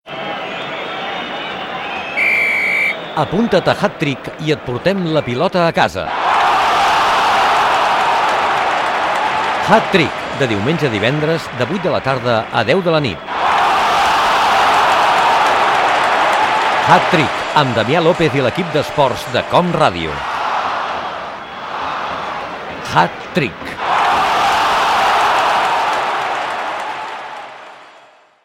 Promoció del programa
Esportiu
Fragment extret de l'arxiu sonor de COM Ràdio.